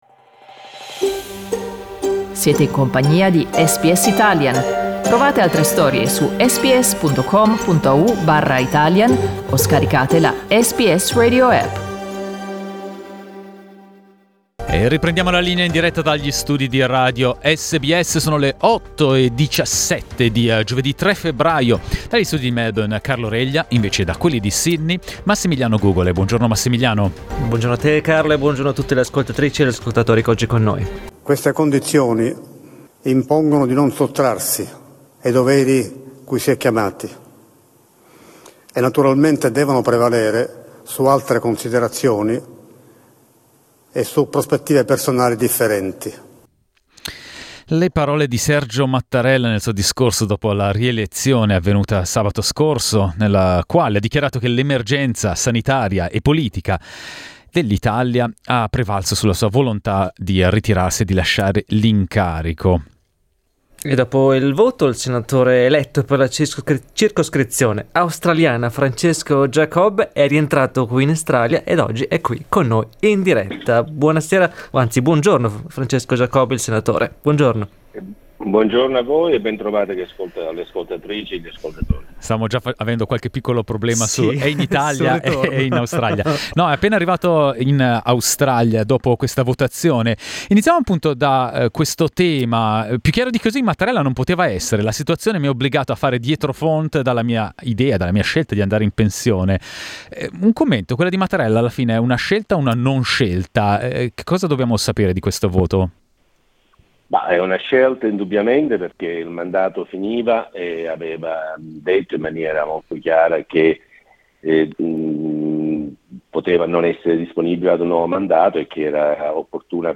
Rientrato in Australia da Roma dopo l'elezione presidenziale, il senatore Francesco Giacobbe commenta la scelta di avviare un secondo mandato di Sergio Mattarella e presenta un'iniziativa per facilitare il riconoscimento del certificato vaccinale australiano in Italia.